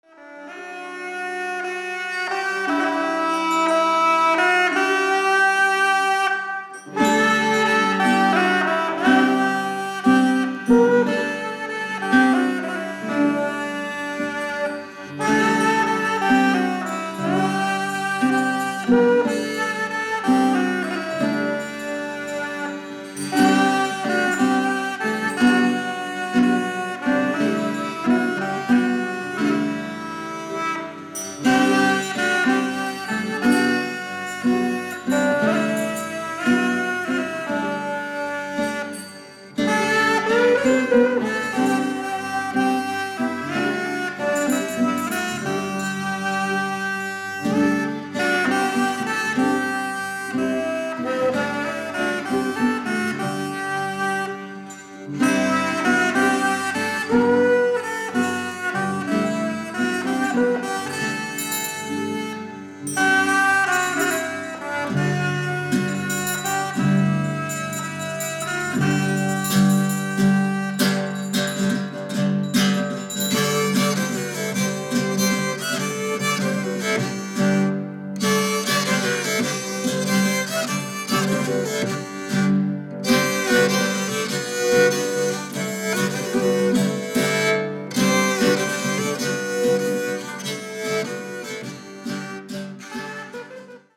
現代人が失った心の息吹、それが、古楽。